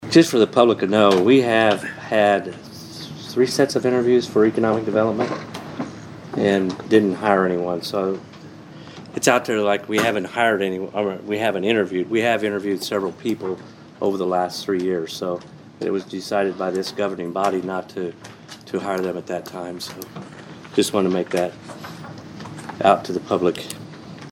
The Vandalia City Council got a short update during Monday’s meeting on personnel.
And, Mayor Rick Gottman further addressed that vacancy.